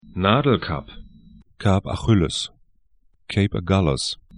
Pronunciation
'na:dlkap